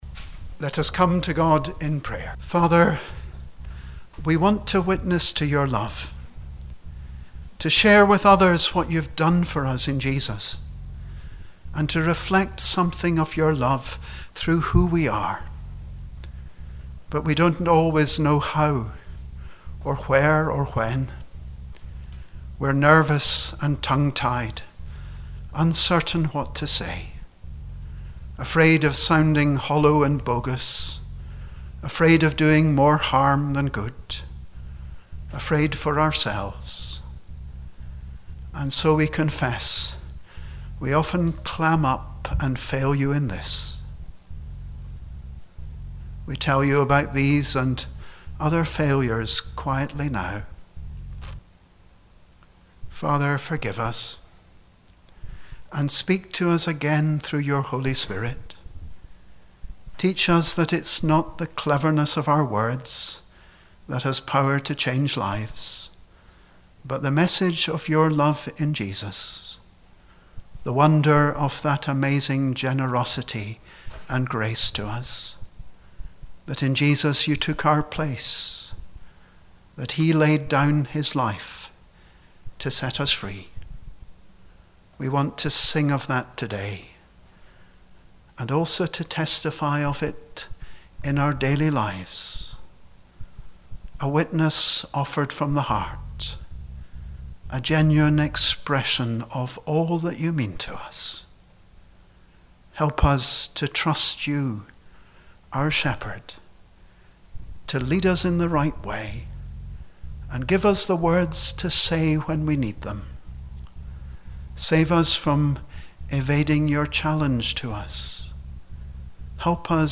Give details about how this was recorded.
Joint Church of Scotland Service - 1 March 2020